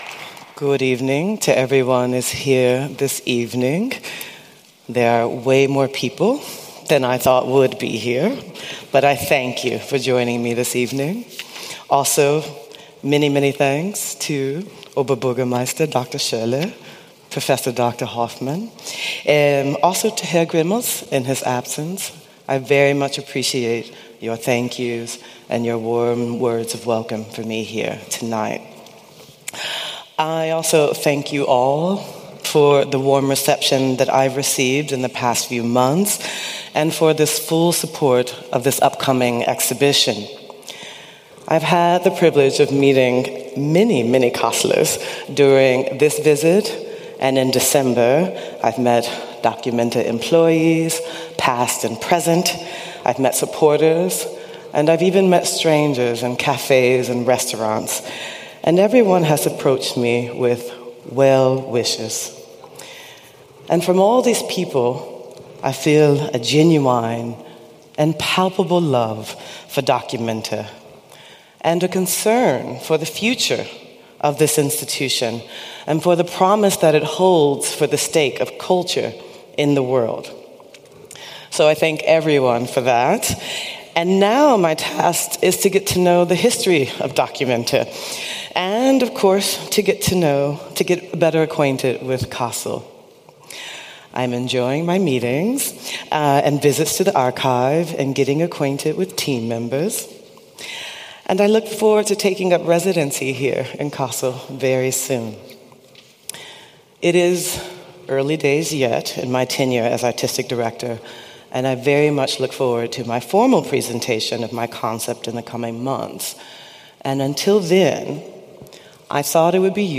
In dieser SprechZeit-Folge dokumentieren wir öffentliche Rede, mit der sich die Kuratorin der documenta 16 am 18. März 2025 in der documenta-Halle der interessierten Öffentlichkeit sowie der nationalen und internationalen Presse vorstellte. Ein Abend, der Fragen aufwirft, Haltungen zeigt und erste Gedanken zur Ausstellung im Jahr 2027 vermittelt. Jetzt reinhören und einen authentischen Eindruck der ersten öffentlichen Positionierung gewinnen – unkommentiert, direkt, mittendrin.